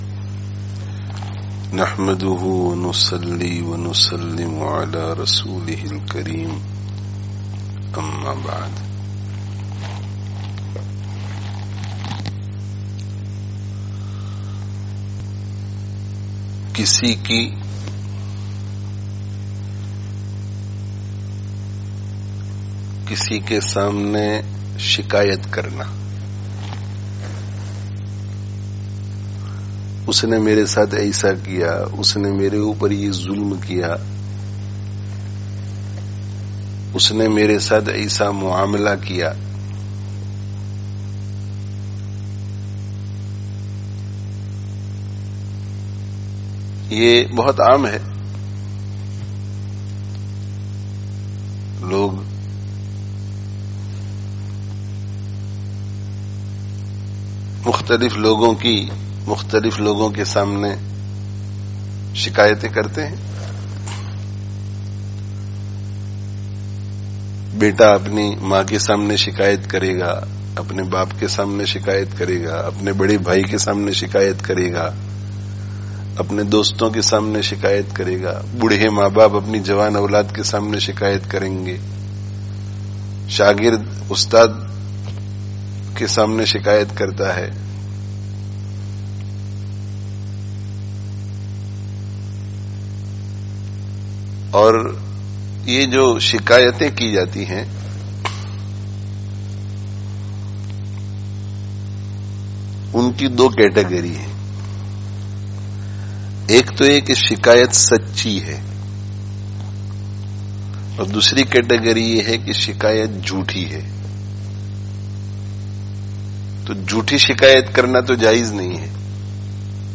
Download friday tazkiyah gathering Urdu 2020 Related articles Allāh ta'ālā kī Farmā(n)bardārī me(n) Kāmyābī hī Kāmyābī hai (14/08/20) Be Shumār Ni'mato(n) ke Bāwajūd Mahrūmī kā Ihsās?